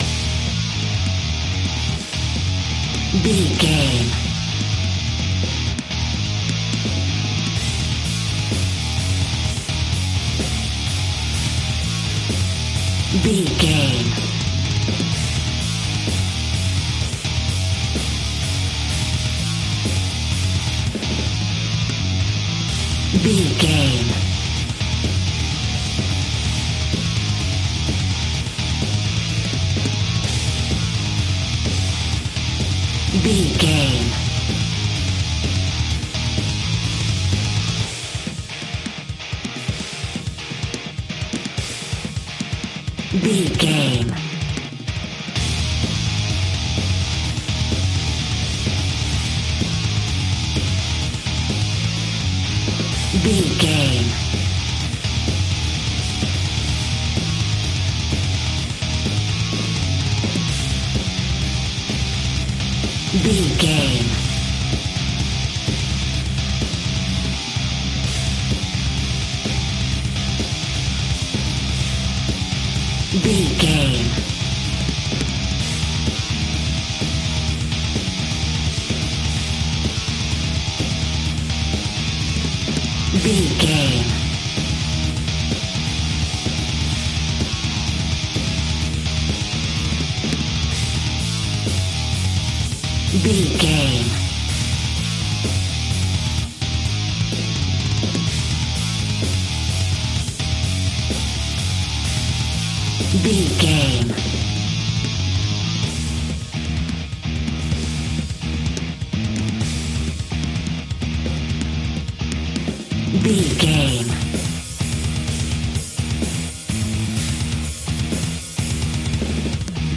Epic / Action
Fast paced
Aeolian/Minor
D♯